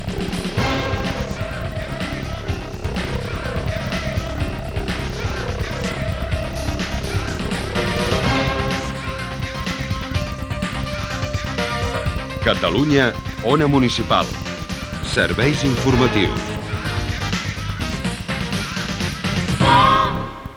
Careta dels serveis informatius.